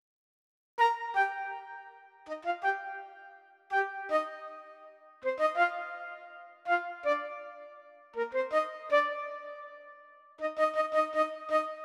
06 flute 1 intro.wav